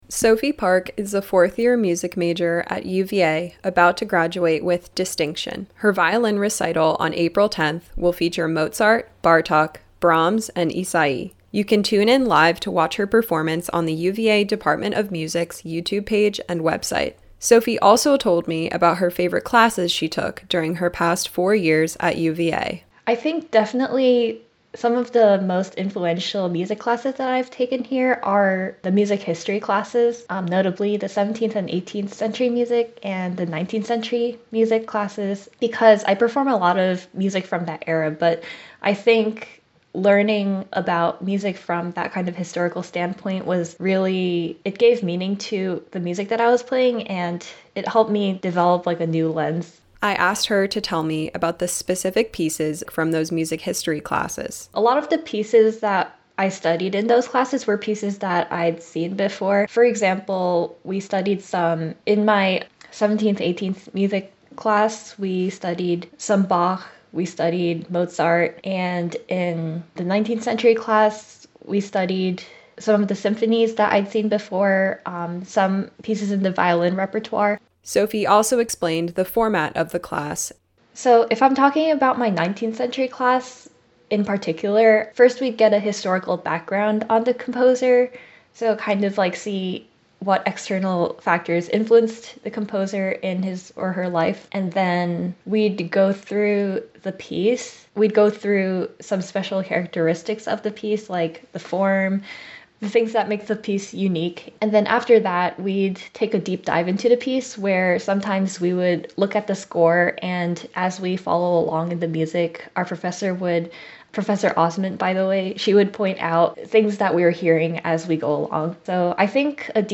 Classical Interviews
These interviews air as part of WTJU’s Classical Sunrise, weekday mornings from 7-9am.